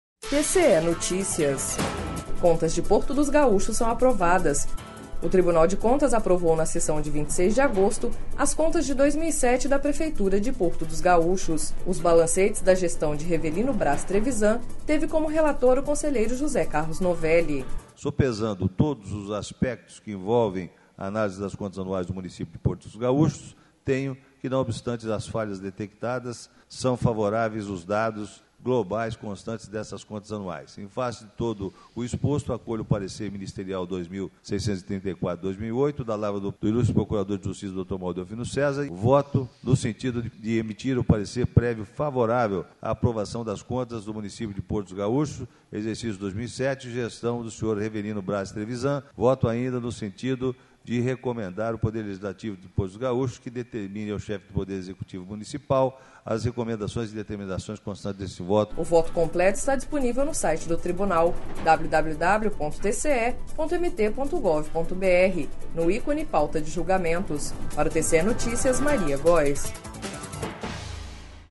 Sonora: José Carlos Novelli – conselheiro TCE-MT